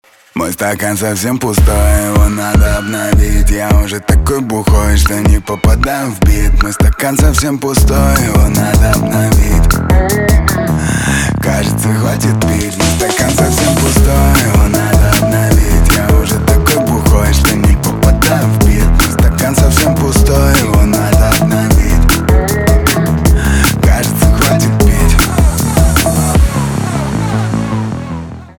русский рэп
битовые , басы , качающие
жесткие